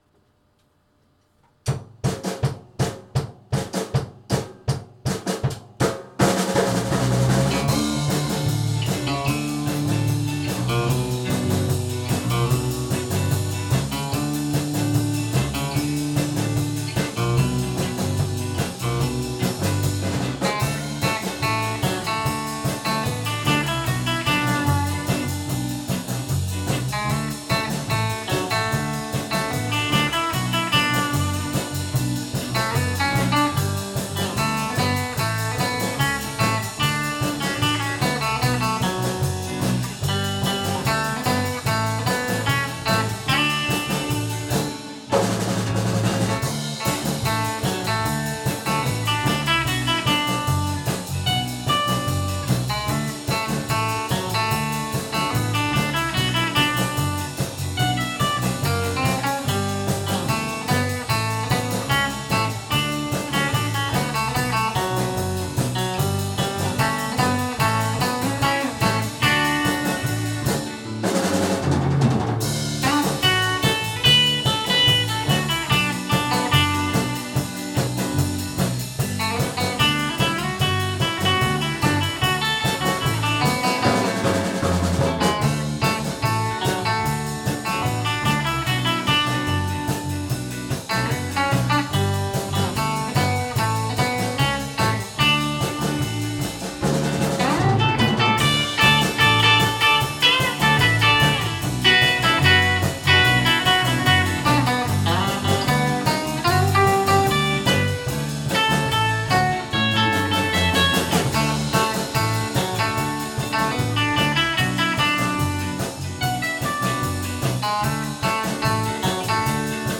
2016 Autumn Live
場所：ベンチャーズハウス「六絃」